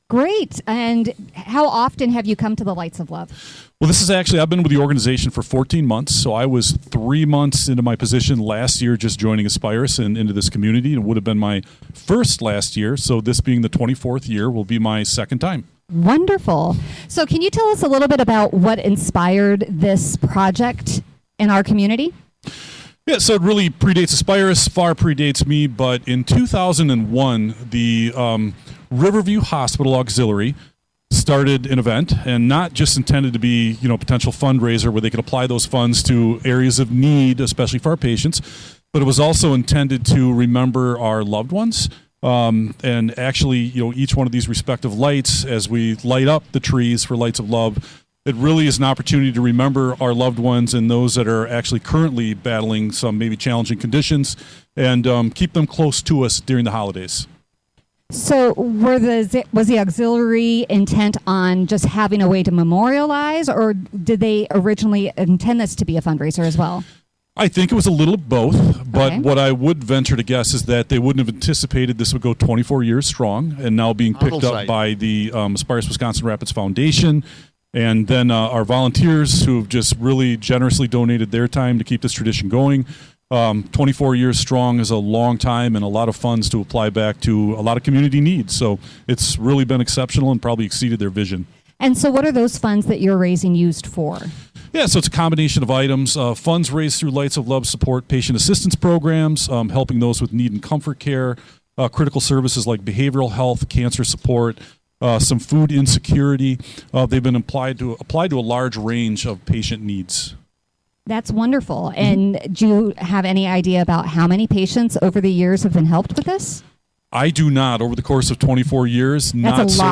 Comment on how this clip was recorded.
wfhr wiri remotes